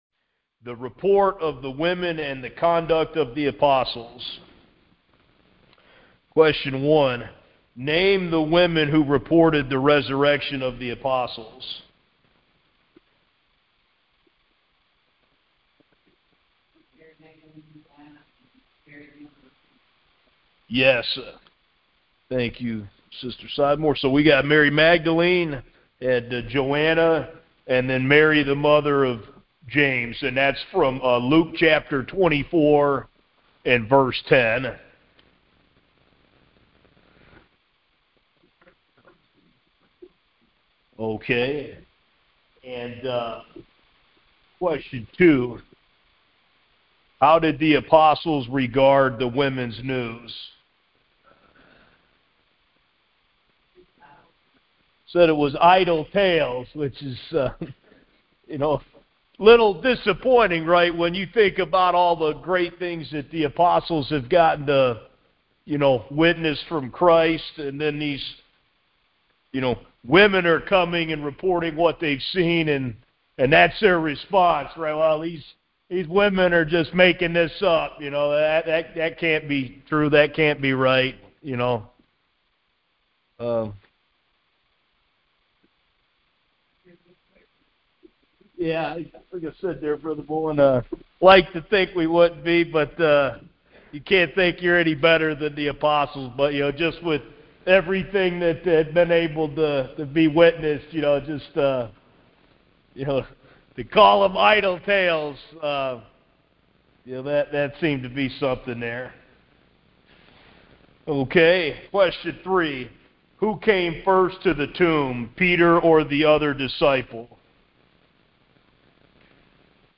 Sunday Morning Service 9.21.25